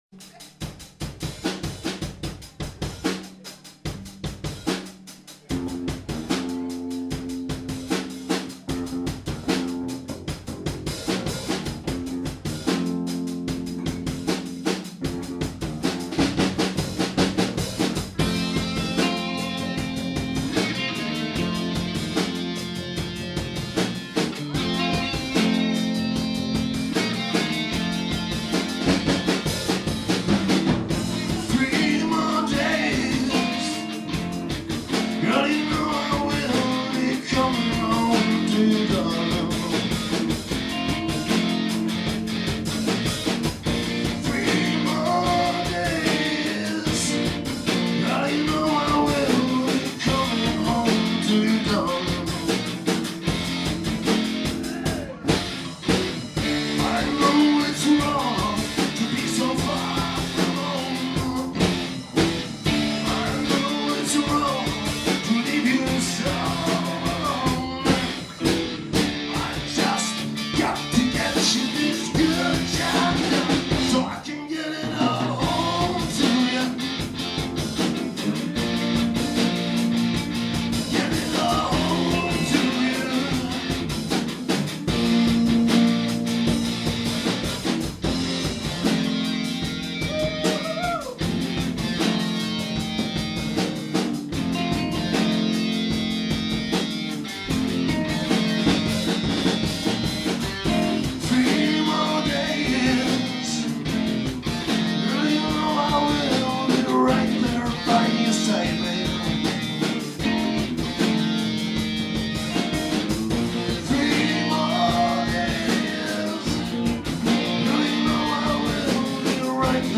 Ukázka ze Staré Pekárny (8.5.2010) -
natočeno na ZOOM H4